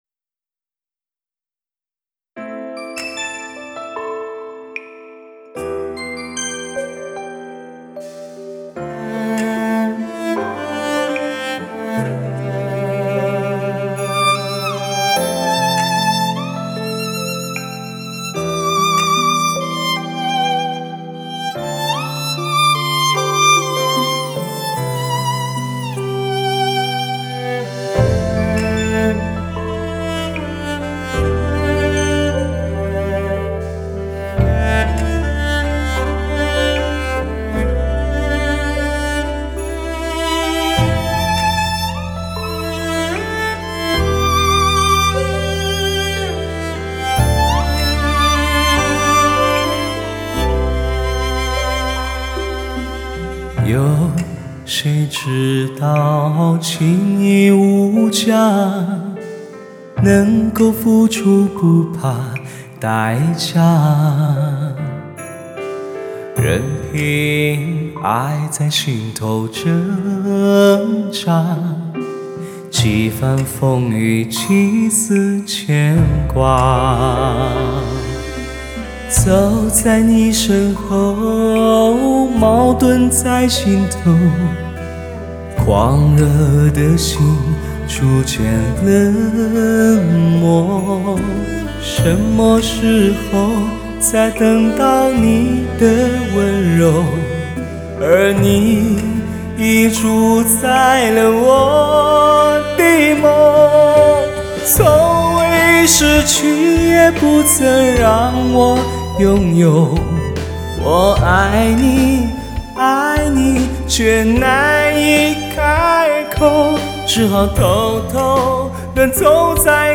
五星级的精湛录音技术，定位一流，层次感兼顾，传神至极